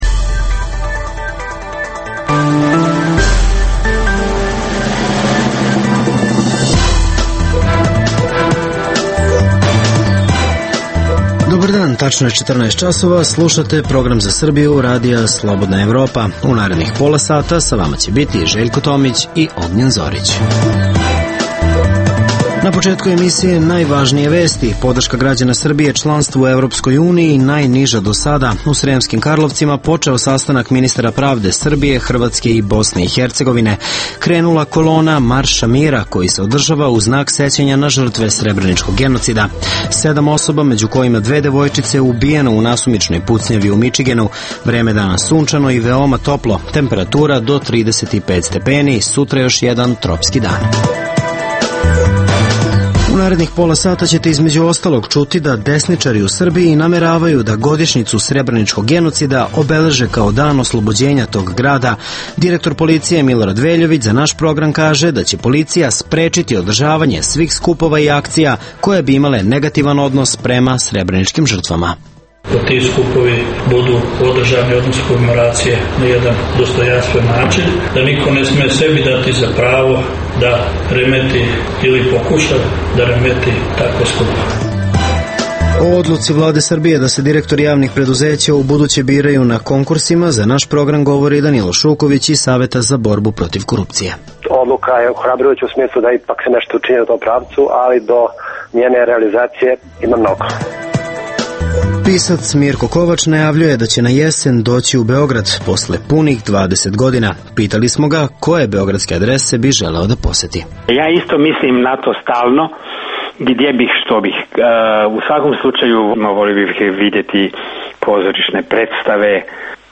O tome za RSE govori direktor policije Milorad Veljović. - Analiziramo odluku Vlade Srbije da se direktori javnih preduzeća ubuduće biraju na konkursima. - Pisac Mirko Kovač u razgovoru za RSE najavljuje da će na jesen doći u Beograd, posle punih 20 godina.